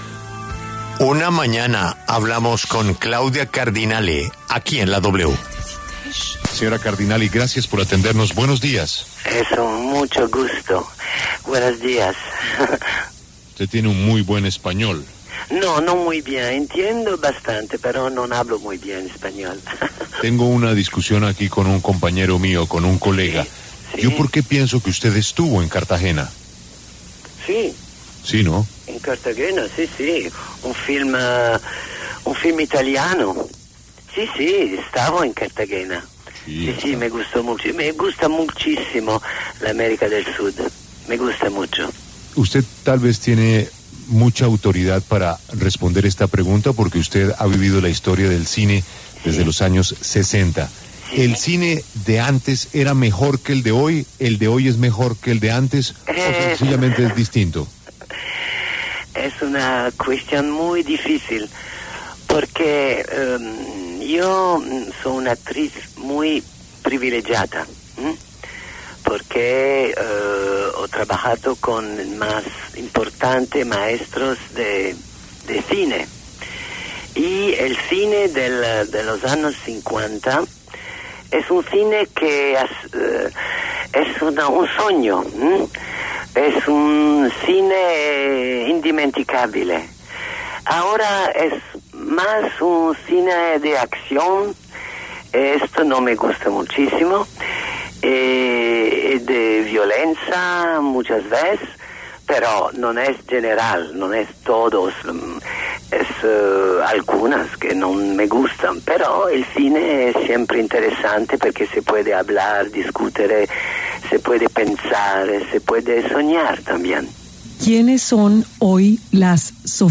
Entrevista con Claudia Cardinale
Con su voz ronca y profunda y su exuberante belleza, Claudia Cardinale fue una estrella del cine de los años 60 y fue musa de directores como Luchino Visconti, Federico Fellini o Sergio Leone.